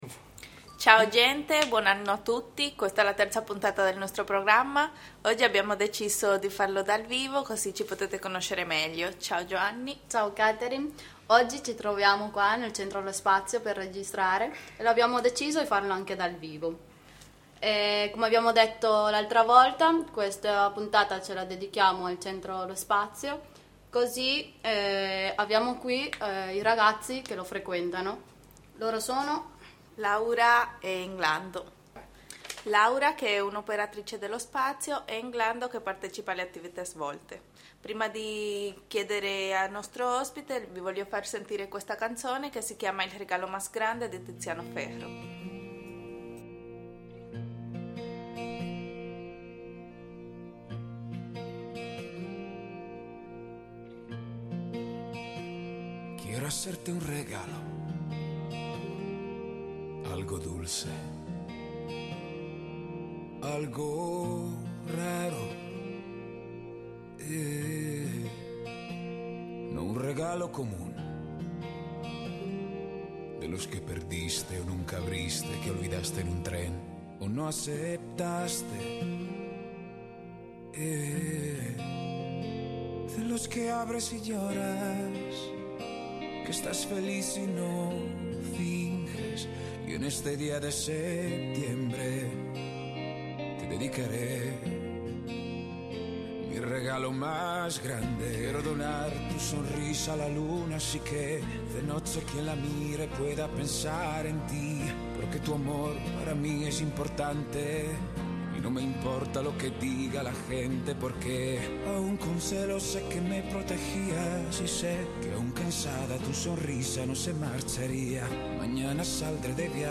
La trasmissione descrive il centro di aggregazione Lo Spazio che ospita la radio; anche tramite le parole dei ragazzi che lo frequentano